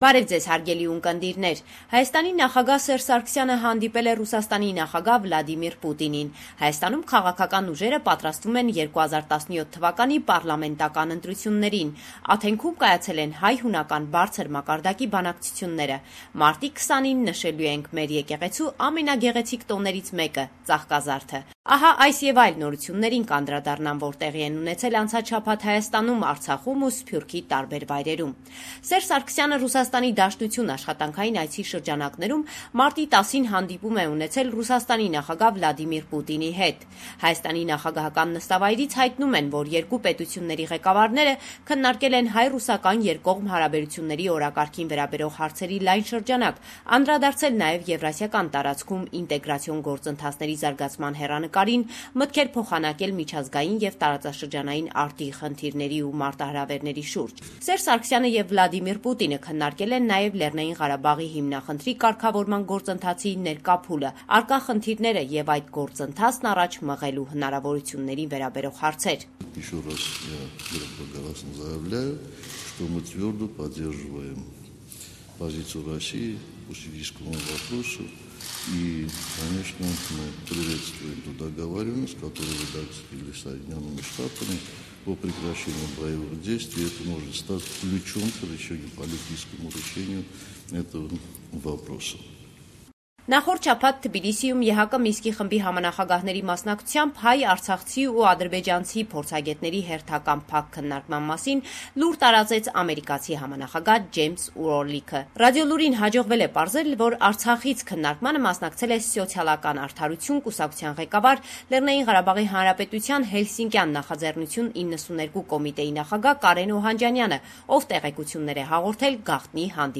LATEST NEWS – 15 March 2015